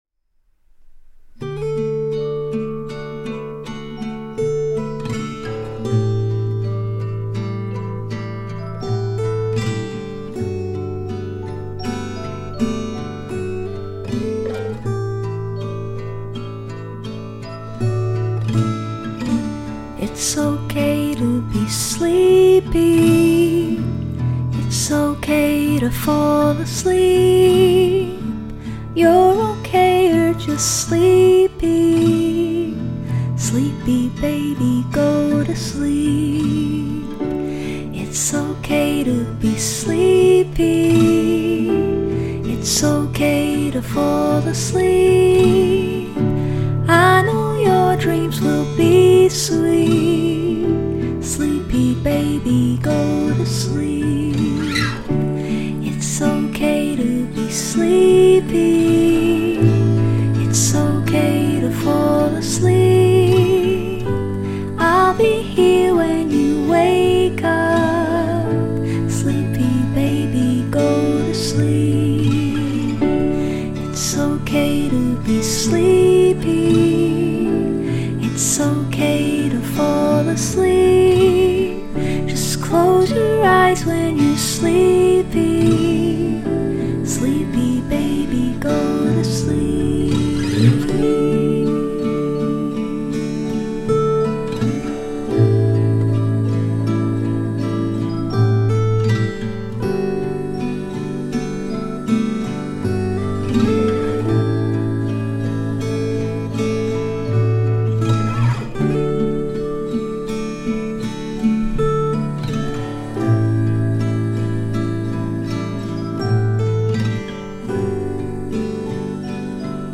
Okay-To-Be-Sleepy-Final-Toy-piano.mp3